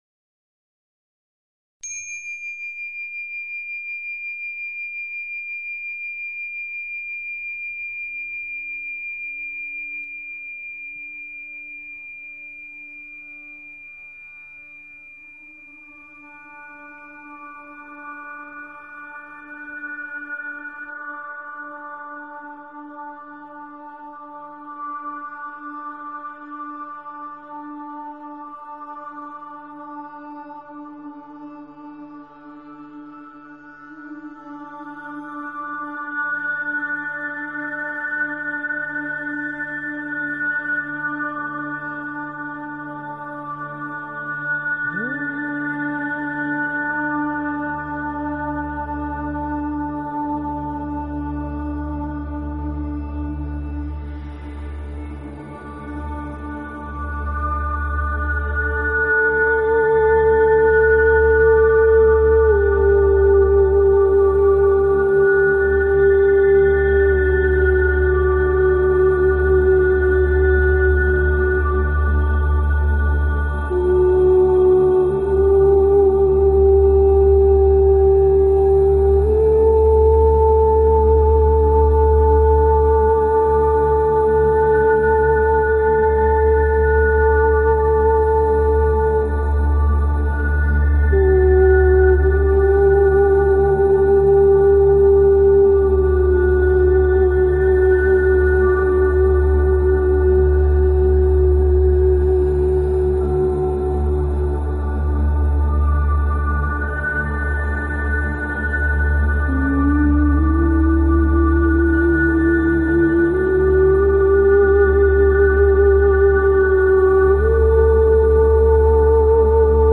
Talk Show Episode, Audio Podcast, Radiance_by_Design and Courtesy of BBS Radio on , show guests , about , categorized as
The show offers you a much needed spiritual tune up – gives you the means to hold your own as you engage the crazy dynamics that occupy our ever changing planet. Radiance By Design is specifically tailored to the energies of each week and your calls dictate our on air discussions.